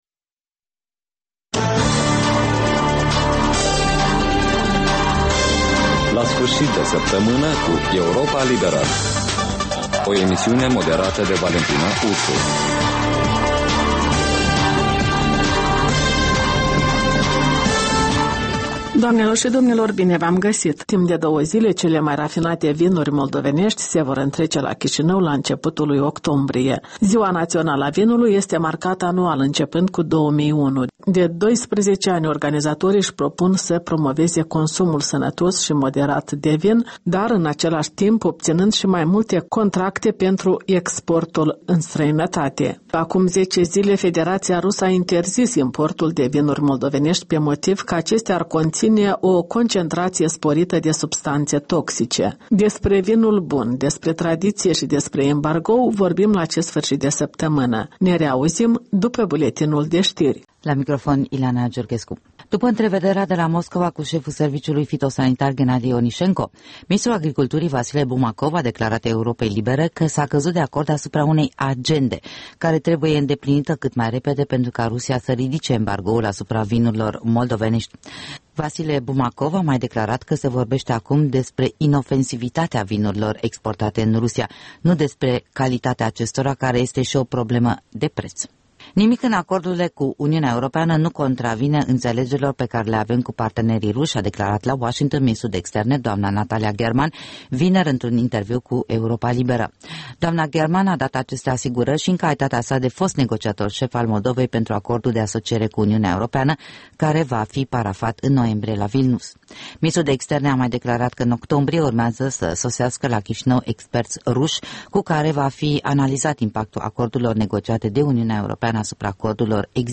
In fiecare sîmbătă, un invitat al Europei Libere semneaza „Jurnalul săptămînal”.